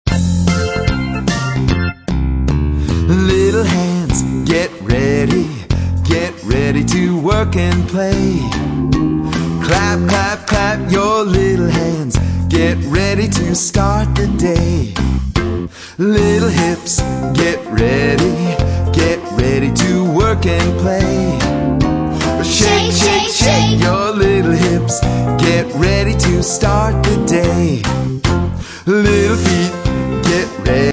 Children's Warm-Up Song for Starting the Day